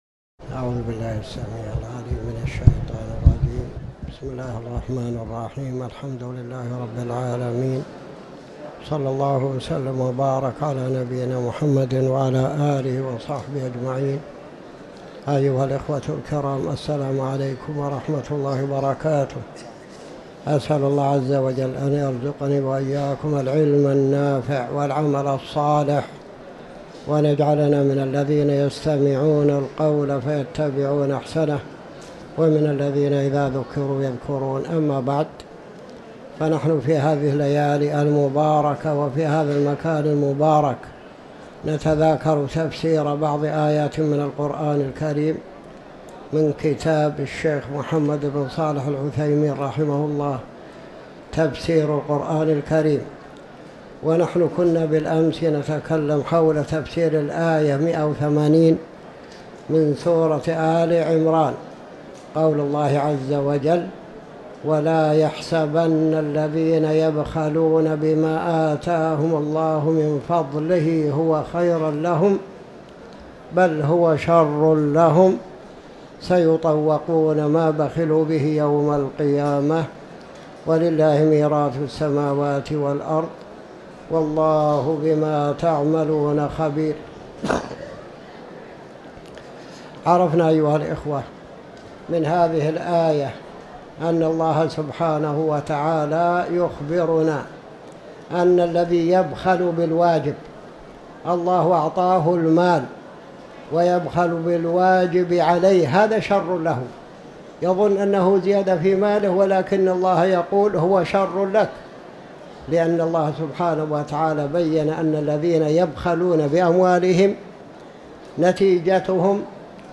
تاريخ النشر ٥ رجب ١٤٤٠ هـ المكان: المسجد الحرام الشيخ